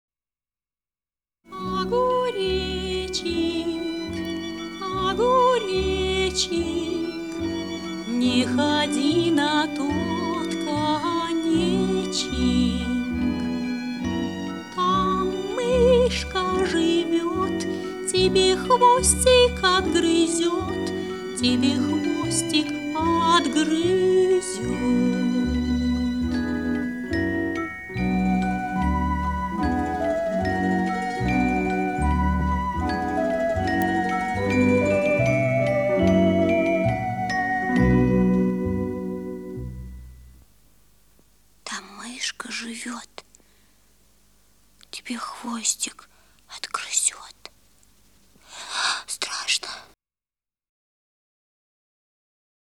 Песни из отечественных мультфильмов
Колыбельные песни